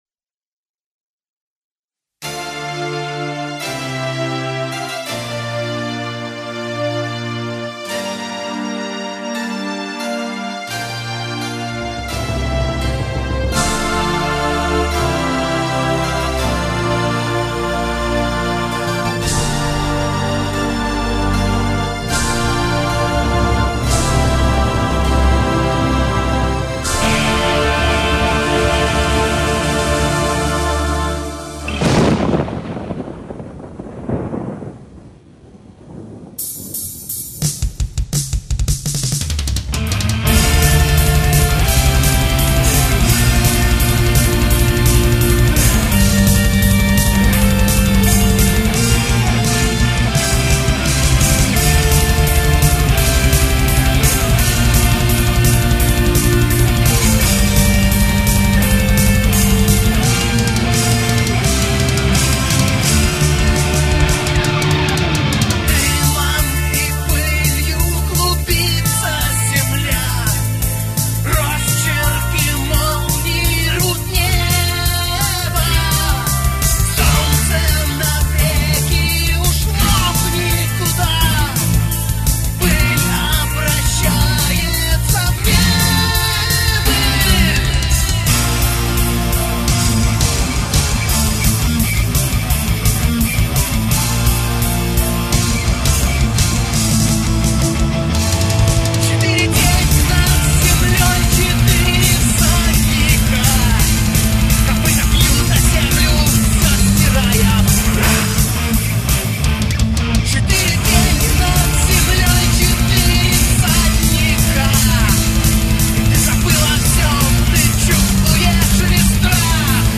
Гитары, бас, клавиши, перкуссия, вокал